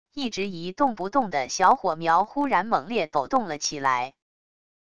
一直一动不动的小火苗忽然猛烈抖动了起来wav音频生成系统WAV Audio Player